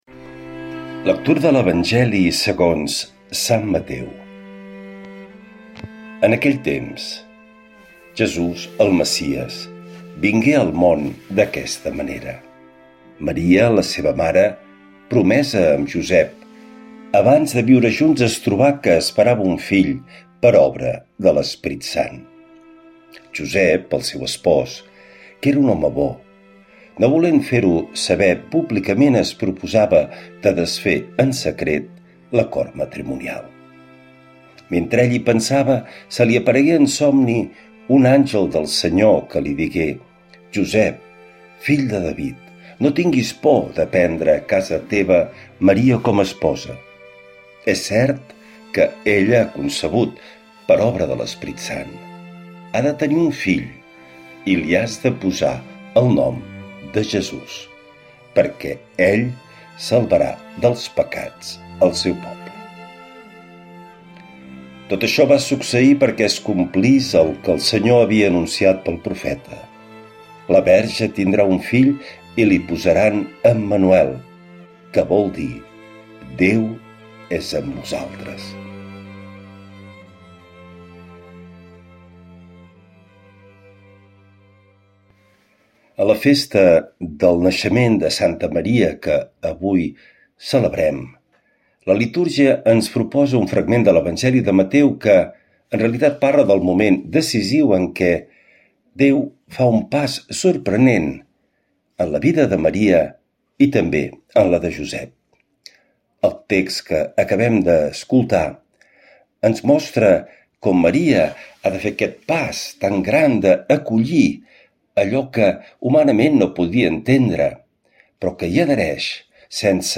L’Evangeli i el comentari de Dilluns 08 de setembre del 2025.